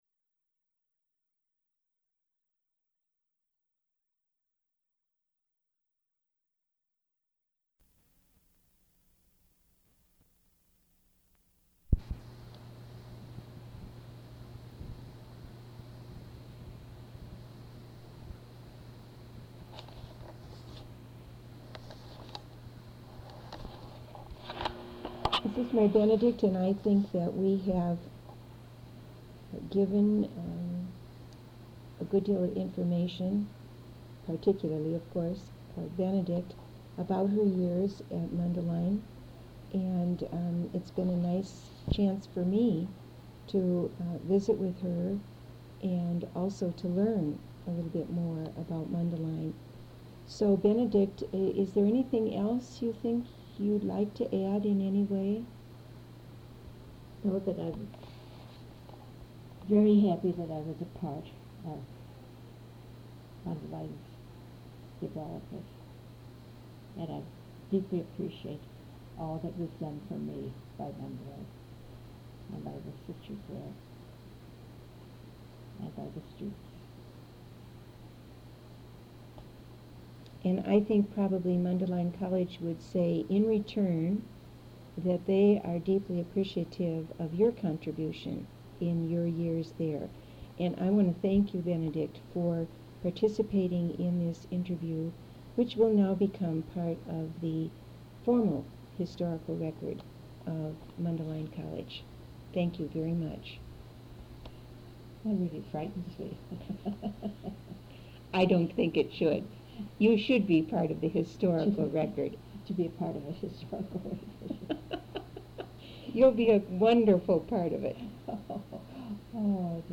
Audio Tape 1 Side A
Interview Transcript